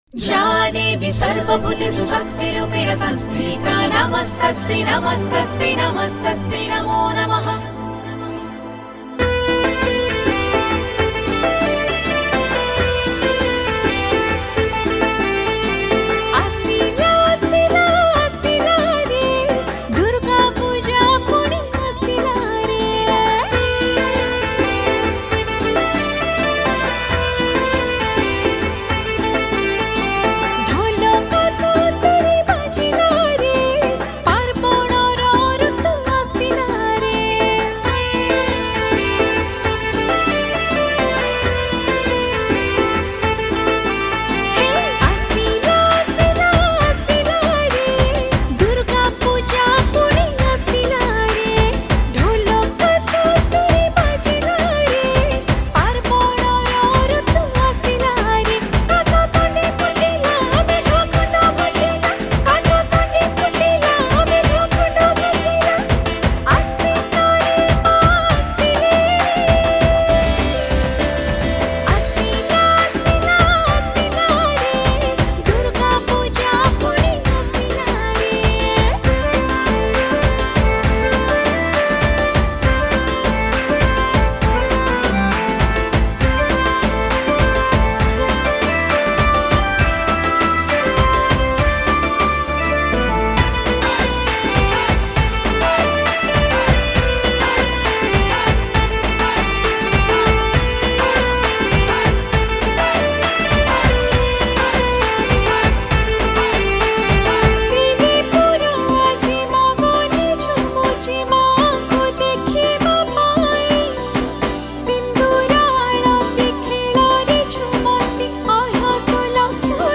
Odia New Bhajan 2026